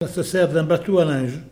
Elle provient de Petosse.
Locution ( parler, expression, langue,... )